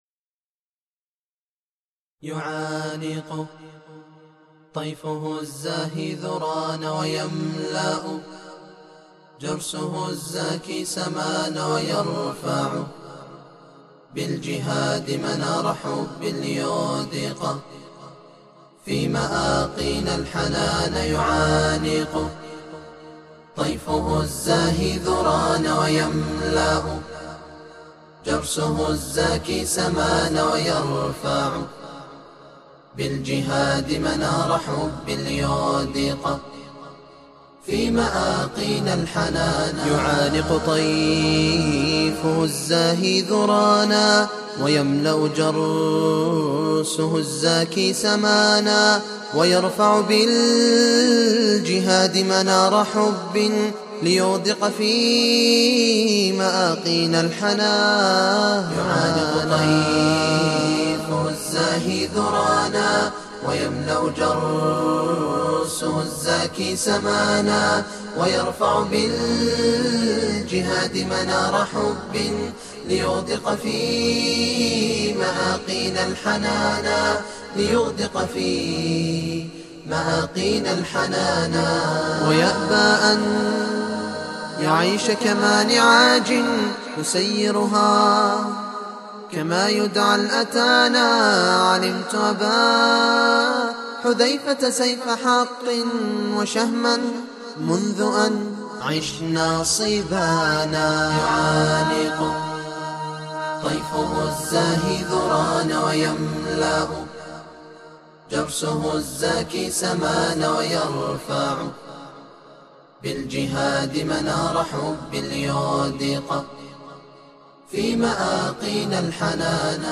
مرثية لأحد الشباب رحمه الله وتقبله !
ـألحان وأداء وهندسة